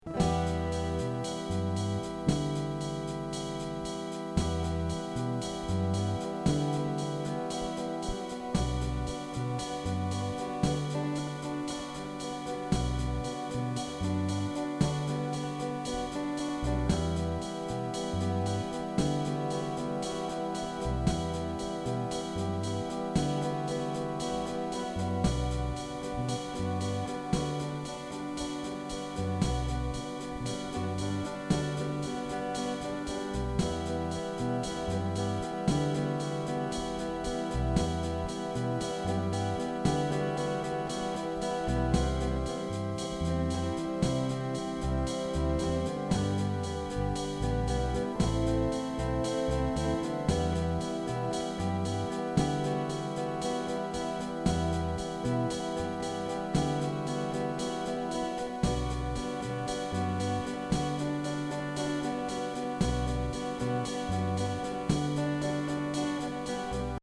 At the moment I think this should go to option 2 or 3, but I self-importantly think it's interesting how the music started as the first one (which sounds even cheesier than I was imagining thanks to some ill-considered reverb or something on the guitar).